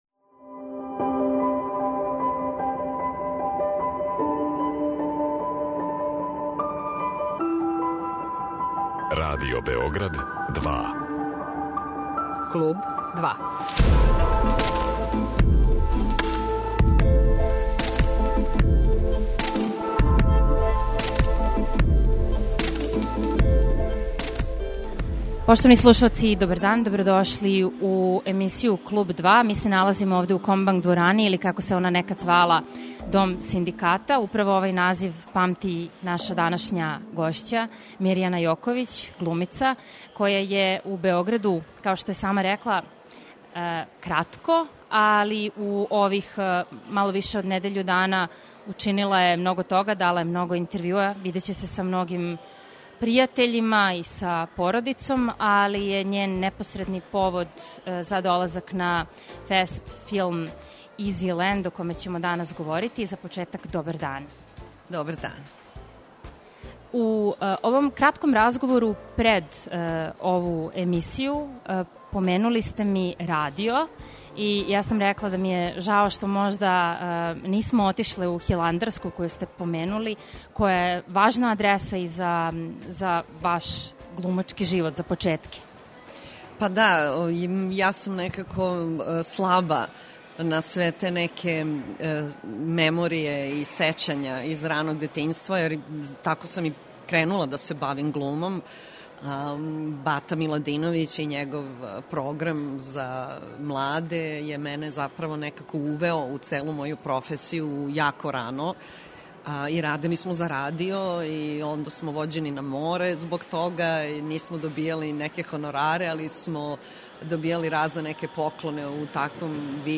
Гошћа данашње емисије у Београду и на ФЕСТ-у је због новог филма Сање Живковић Easy land, у коме тумачи главну улогу.